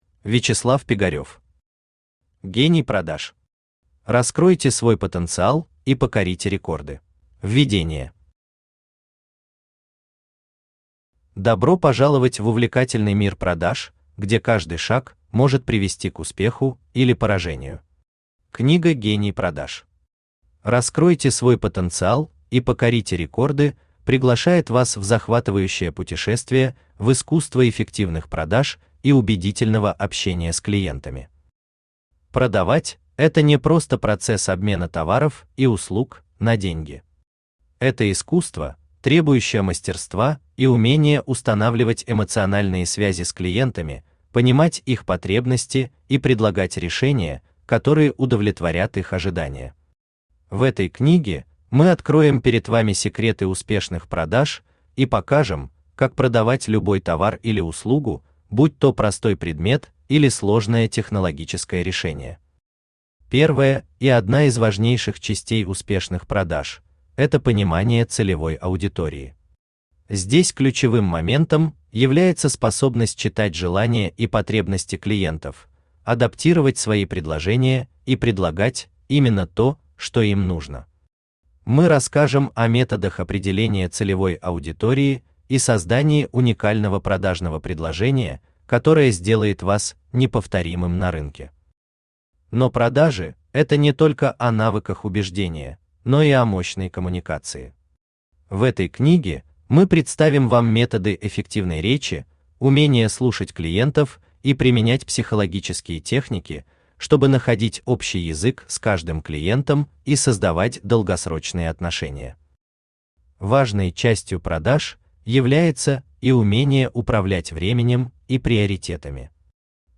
Раскройте свой потенциал и покорите рекорды Автор Вячеслав Пигарев Читает аудиокнигу Авточтец ЛитРес.